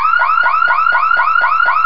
1 channel
CARALARM.mp3